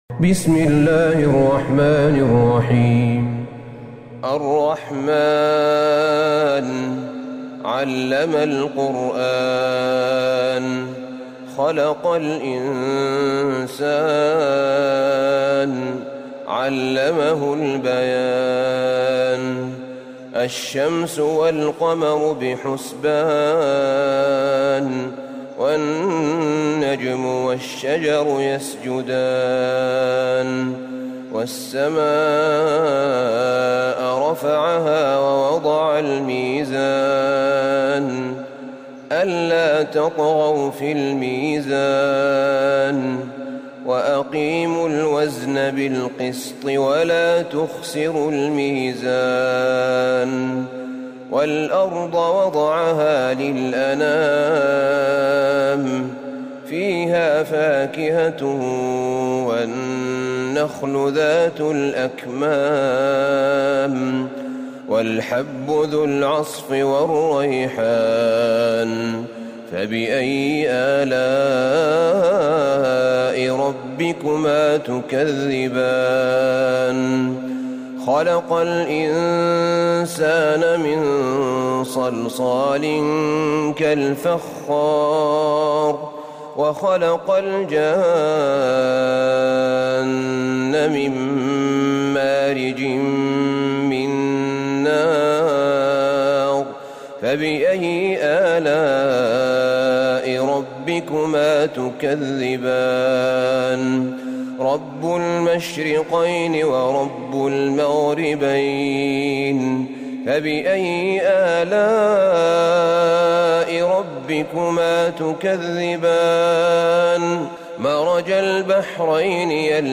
سورة الرحمن Surat ArRahman > مصحف الشيخ أحمد بن طالب بن حميد من الحرم النبوي > المصحف - تلاوات الحرمين